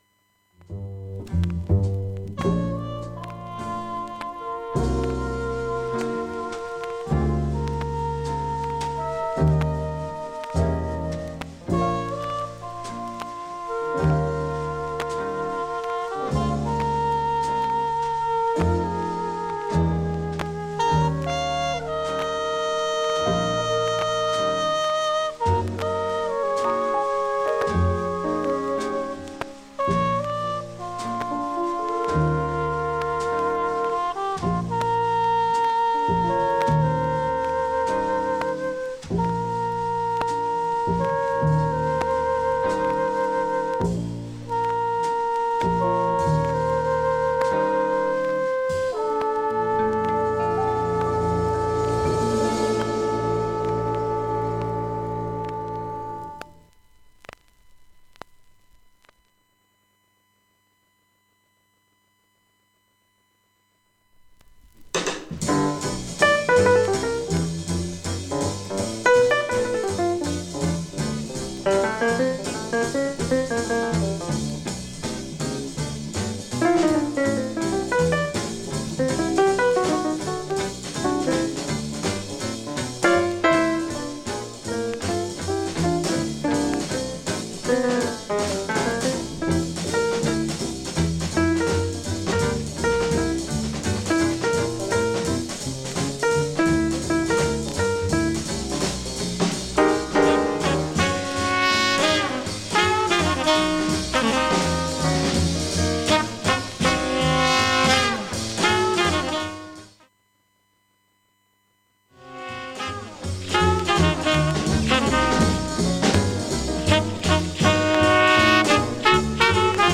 B面ラスト40秒ほどはチリプツ大きめですが
B-2序盤、後半静かな部にチリプツ出ますが、
音自体は大きくありません。
黄NYC DG RVG MONO